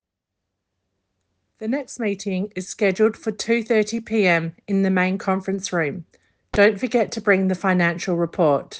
1. Australian accent: Schedule